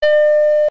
Mac OS System 1 Startup.mp3